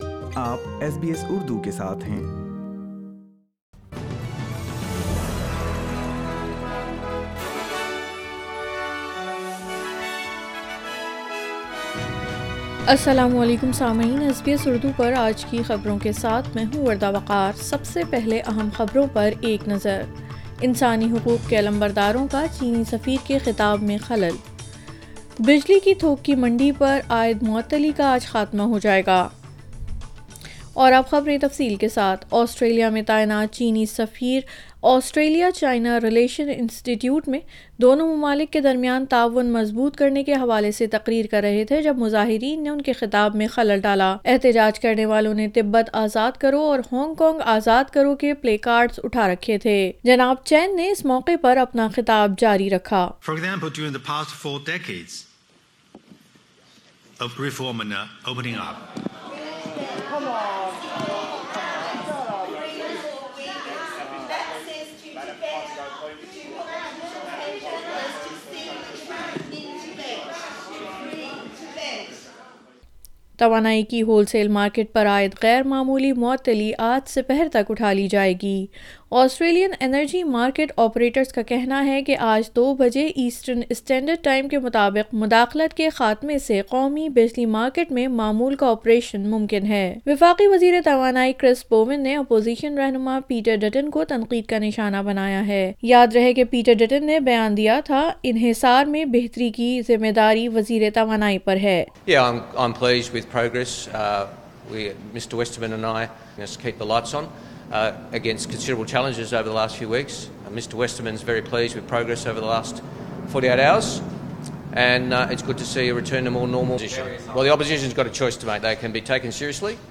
SBS Urdu News 24 June 2022